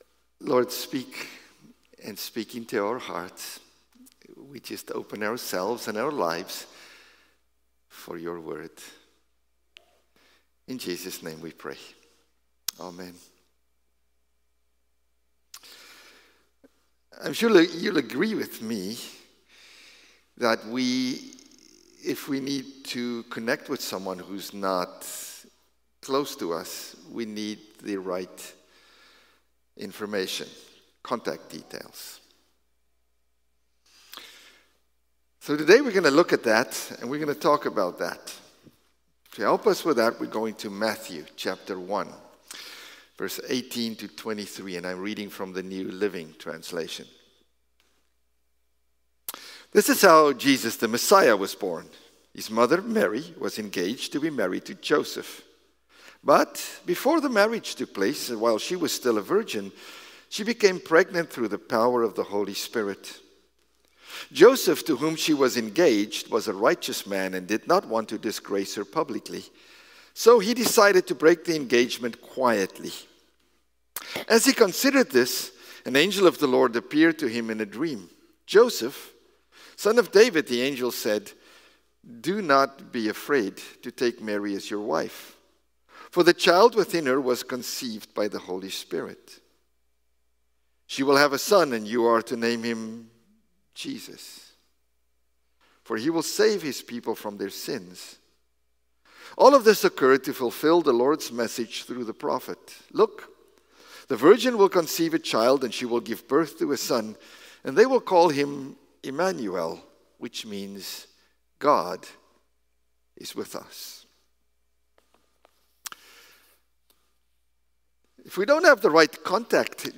December-29-Sermon-1.mp3